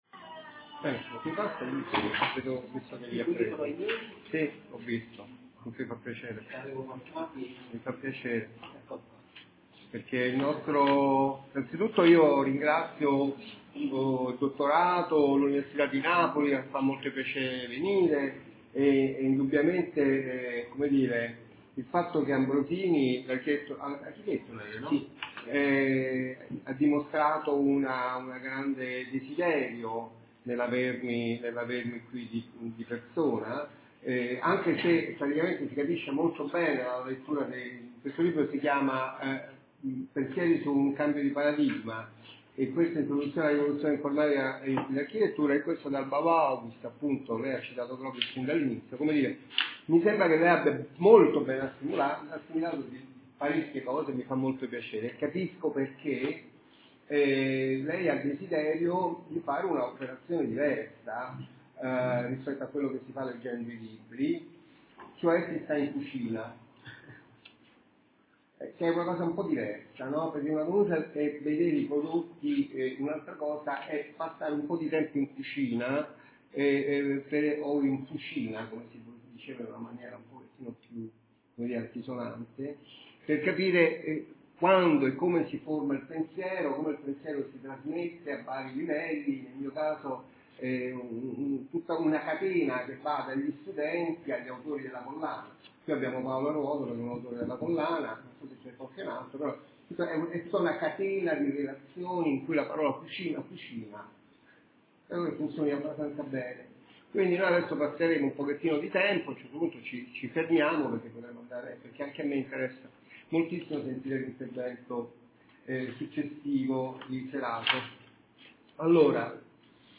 Lezione